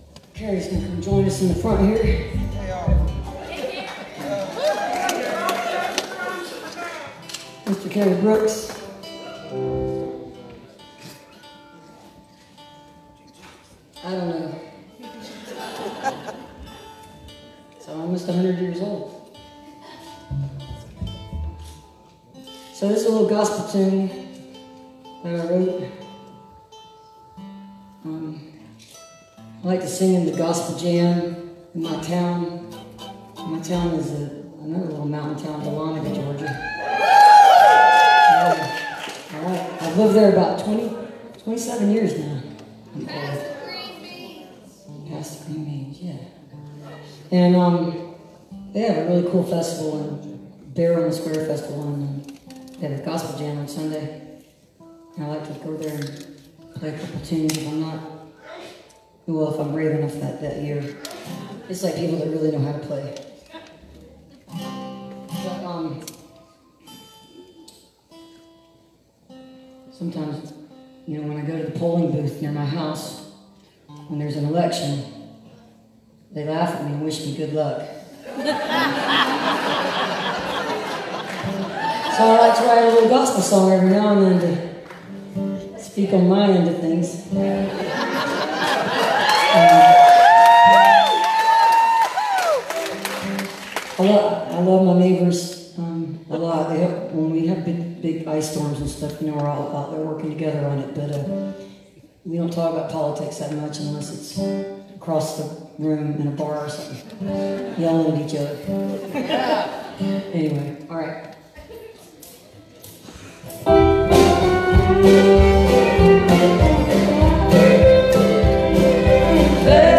(captured from facebook live stream)